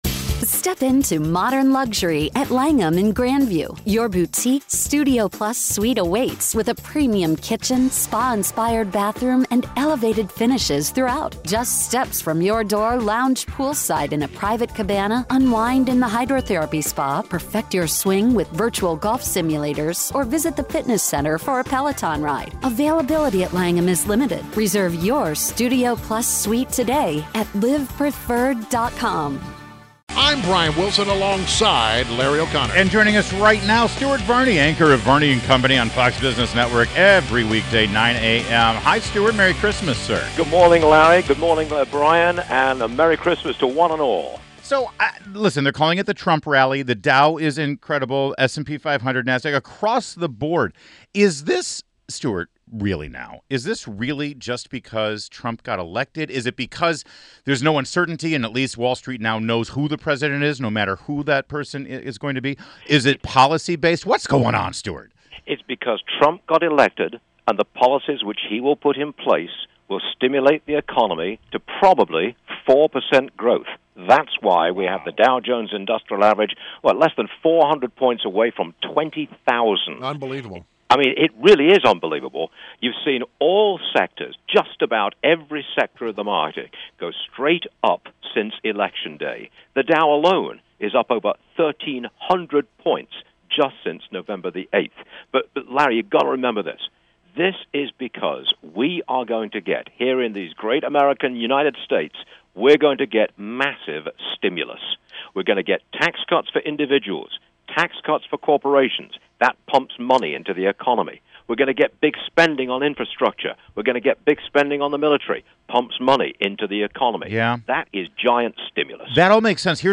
INTERVIEW — STUART VARNEY – Anchor of “Varney and Company” on FOX BUSINESS NETWORK, airing Weekdays at 9AM ET